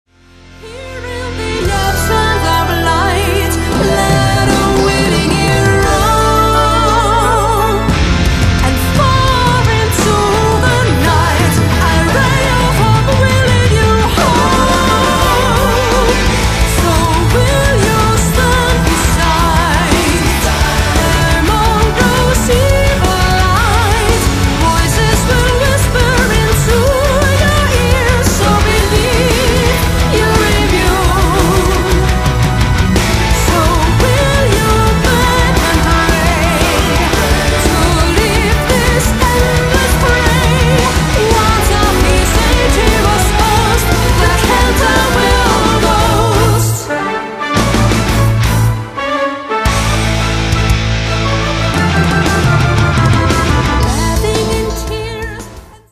* Symphonic Power Metal *